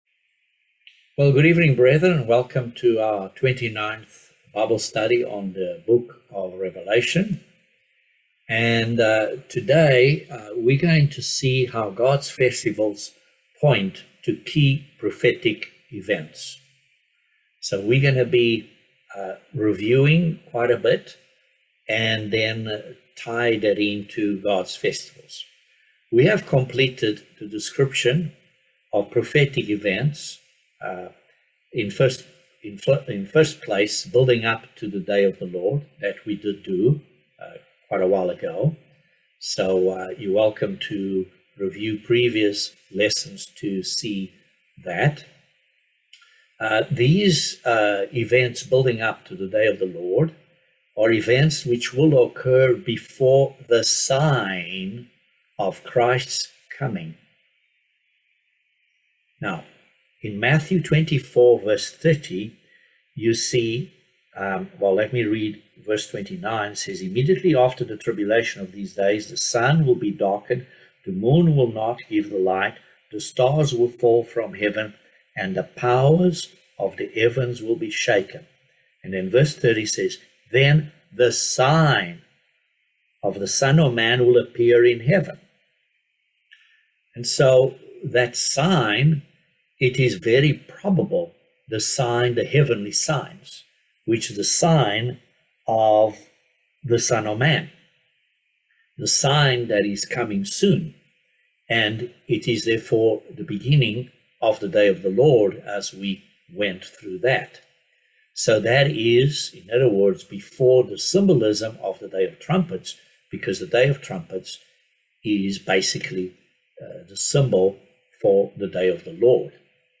Bible Study No 29 of Revelation